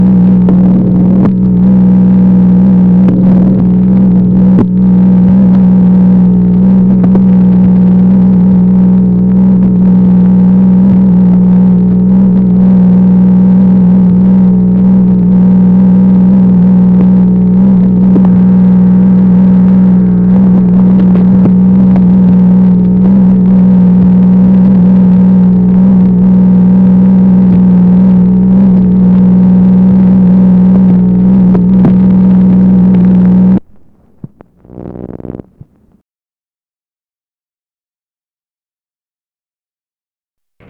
MACHINE NOISE, February 19, 1964
Secret White House Tapes | Lyndon B. Johnson Presidency